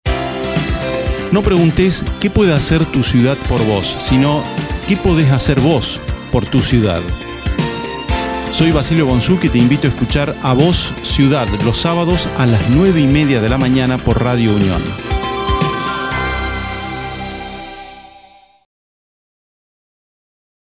Descargar audio: Promo del programa de radio